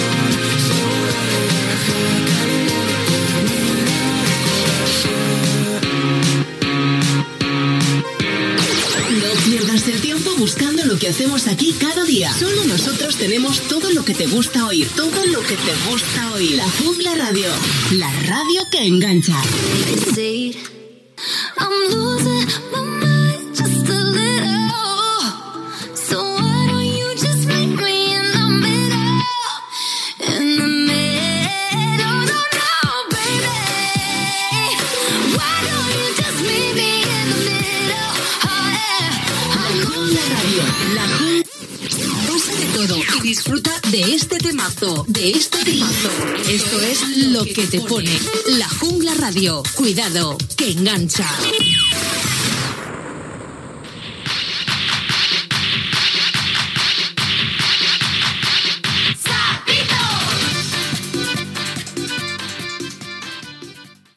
Temes musicals i indicatius de la ràdio